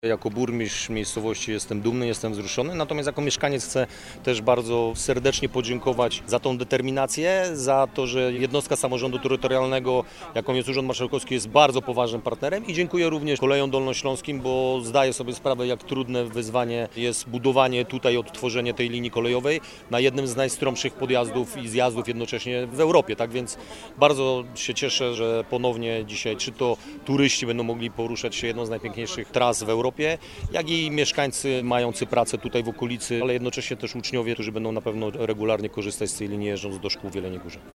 Skorzystają i turyści, i mieszkańcy okolicznych miejscowości takich jak Łomnica, Mysłakowice czy Miłków zaznacza Radosław Jęcek, Burmistrz Karpacza.